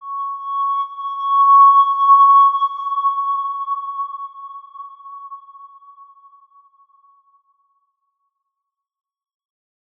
X_Windwistle-C#5-pp.wav